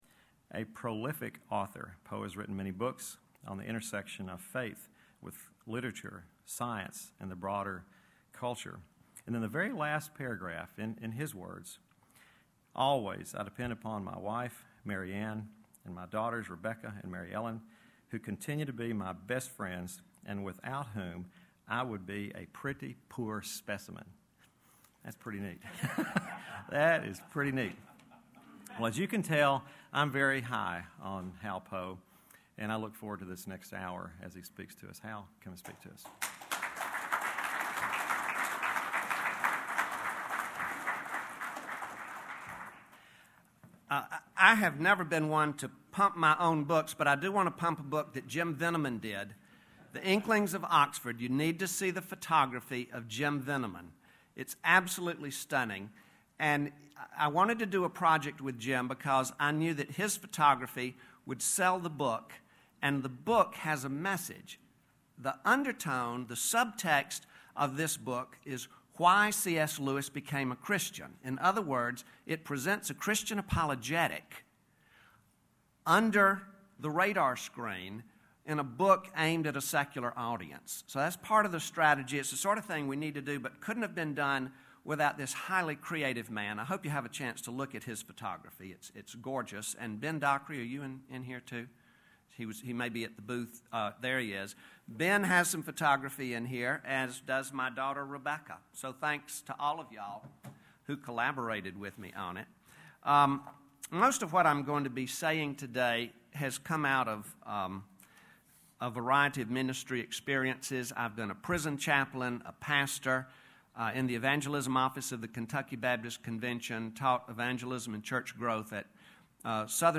Future of Denominationalism Conference
Address: The Gospel and Its Meaning: Implications for Southern Baptists and Evangelicals Recording Date